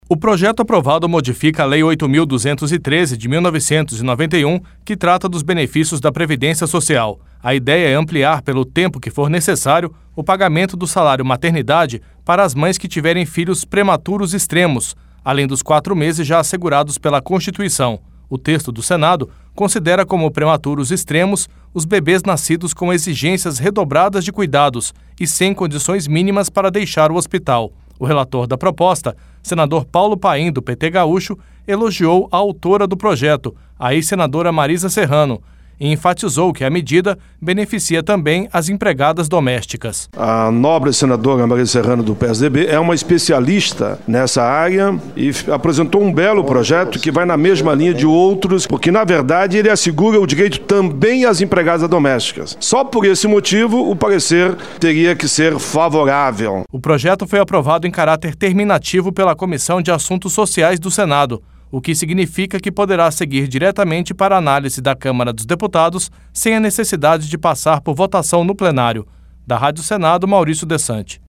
O relator da proposta, senador Paulo Paim, do PT gaúcho, elogiou a autora da proposta, a ex-senadora Marisa Serrano, e enfatizou que a medida beneficia também as empregadas domésticas: